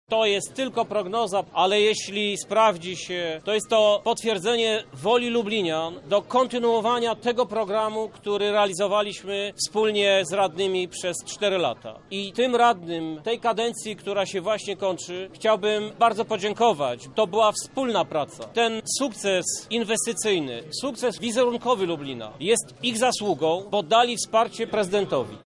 Wierzę w to, że wyborcy chcą współodpowiadać za rozwój naszego miasta – mówi prezydent Krzysztof Żuk